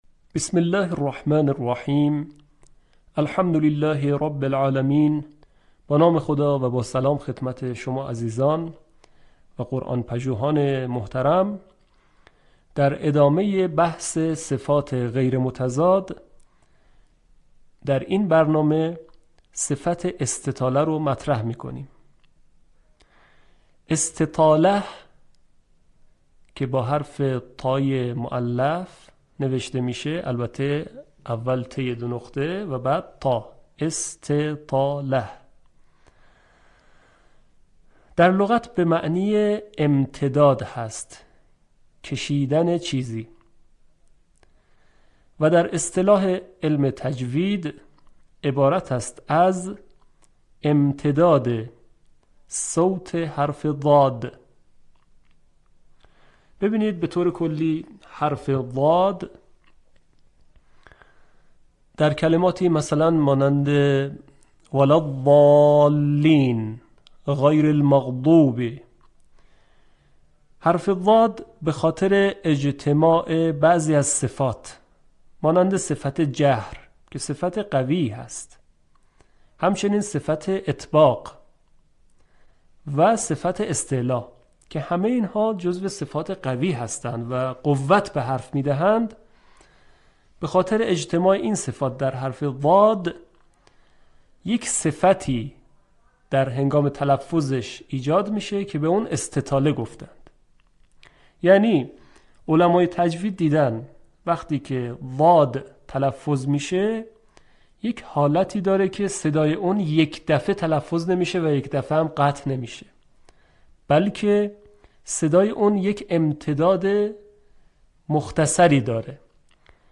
به همین منظور مجموعه آموزشی شنیداری (صوتی) قرآنی را گردآوری و برای علاقه‌مندان بازنشر می‌کند.
برچسب ها: پای رحل قرآن ، آموزش قرآن ، آموزش تجوید